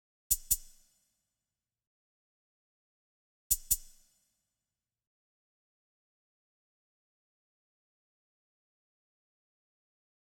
Closed Hats
Beast_Hi Hat #2.wav